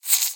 Sound / Minecraft / mob / silverfish / say4.ogg